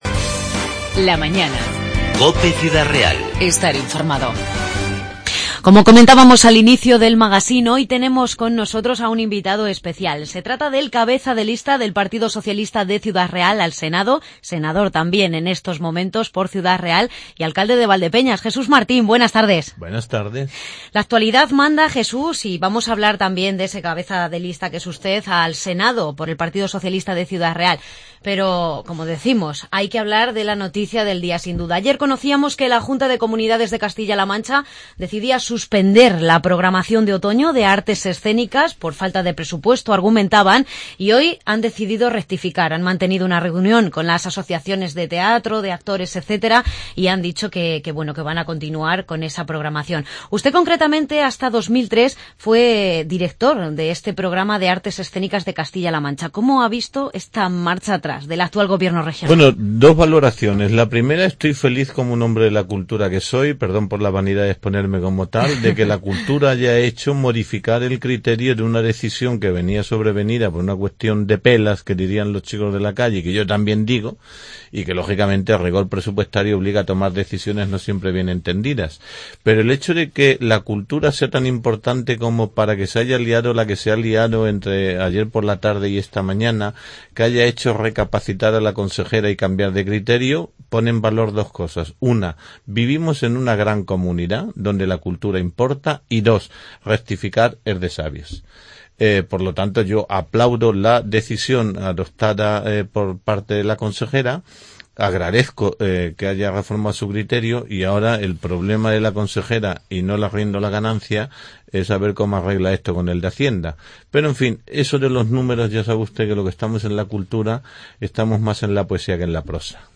Entrevista Jesús Martín, alcalde de Valdepeñas 9-10-15